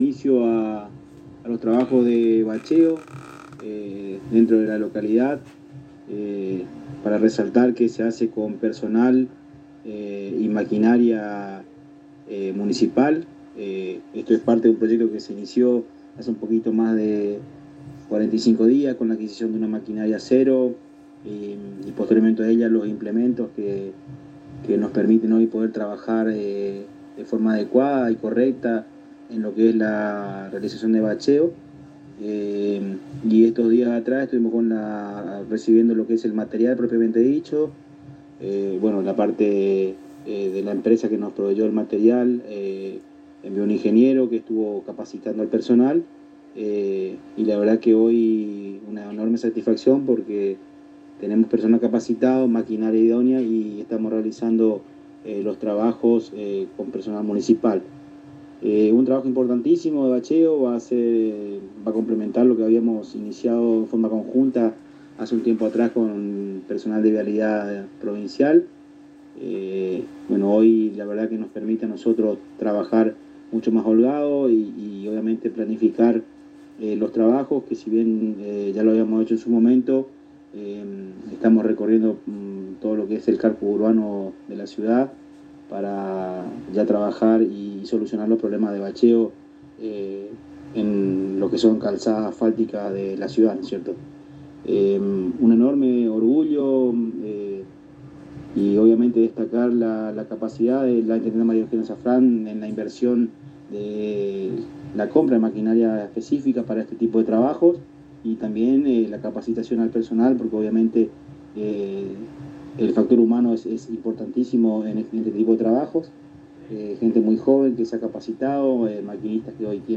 El Secretario de Obras Públicas Juan José «Juanji» Ferreyra en diálogo exclusivo con la ANG contó con mucha satisfacción que la Intendente resolvió comprar la maquinaria necesaria y capacitó al personal para realizar el propio bacheo de asfaltado de las calles de la ciudad.
Audio: Sec. Ob. Pub. Juan José Ferreyra.